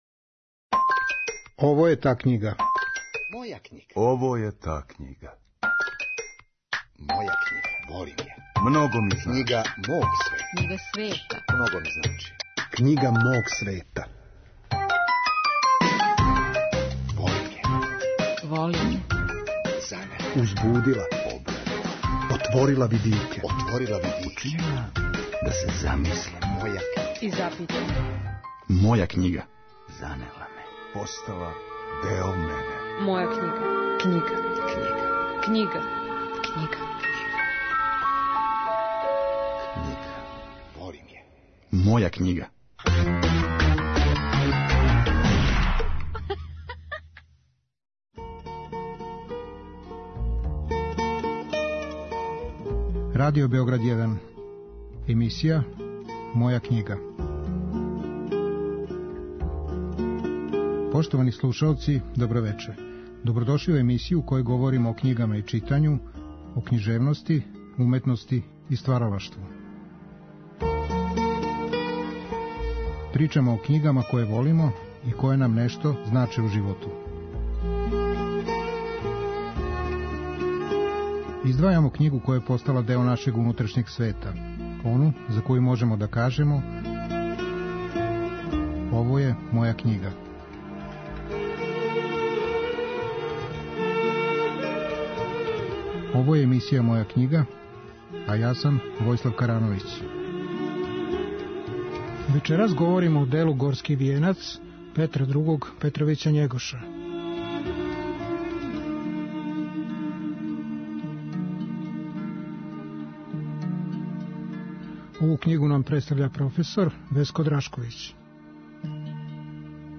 Наш саговорник говори о томе када и како се први пут срео са ''Горским вијенцем'', и зашто се овом делу непрестано враћа. Биће речи о слојевитости овог дела, о историјском контексту, књижевним вредностима, језику и мисаоности ''Горског вијенца''.